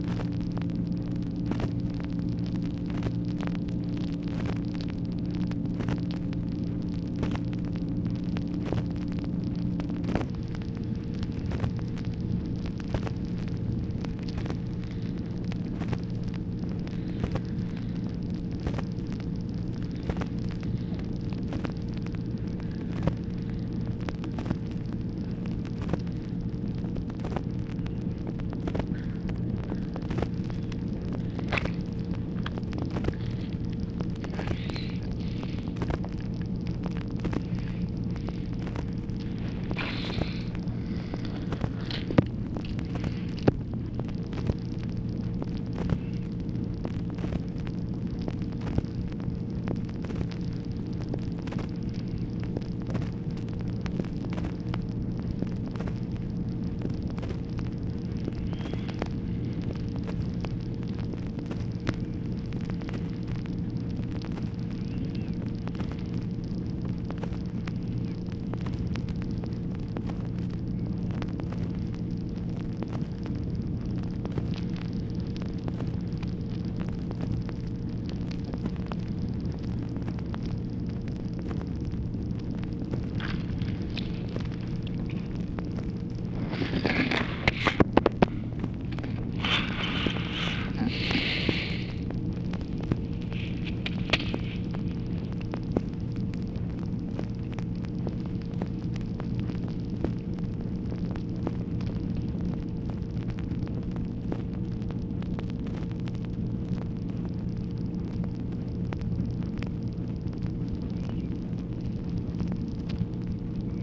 OFFICE NOISE, November 16, 1964
Secret White House Tapes | Lyndon B. Johnson Presidency